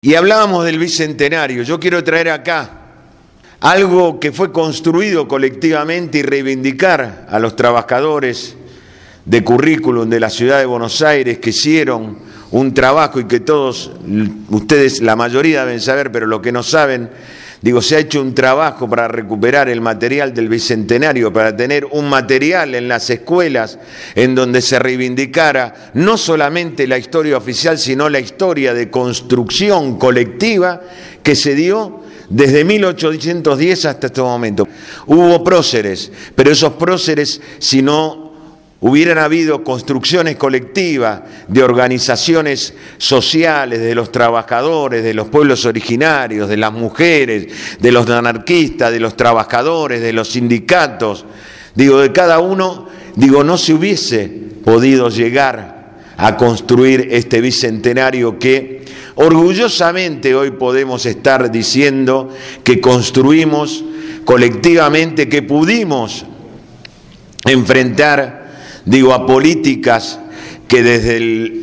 Organizado por Radio Gráfica y el programa «Cambio y Futuro», el último 19 de Mayo se realizó en la instalaciones de la radio la charla debate «Educación en el Bicentenario«.
Ante un centenar de presentes, la expectativa era mucha porque el panel de invitados así lo proponía: